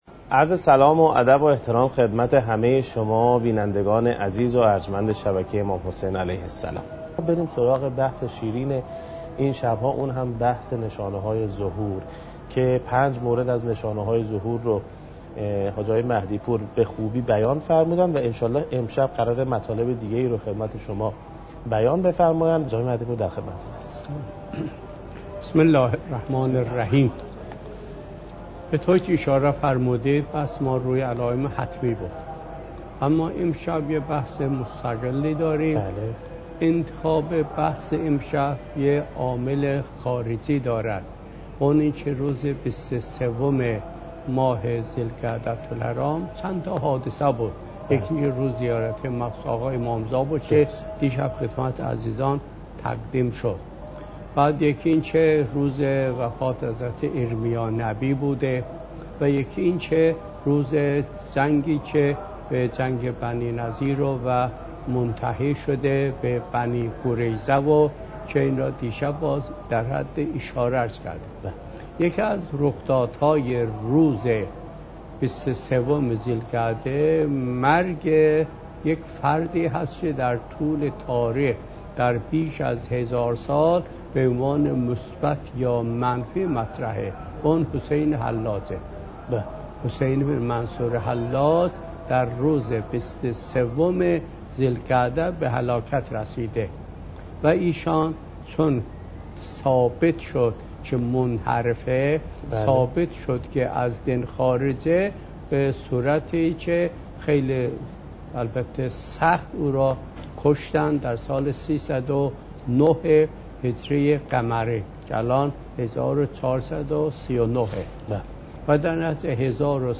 حجم: 12.4 MB | زمان: 53:04 | تاریخ: 1439هـ.ق | مکان: کربلا